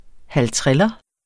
halvtreller substantiv, fælleskøn Bøjning -en, -e, -ne Udtale [ halˈtʁεlʌ ] Oprindelse kendt fra 2006 spøgende efterligning af halvtredser Betydninger slang pengeseddel med værdien 50 kr.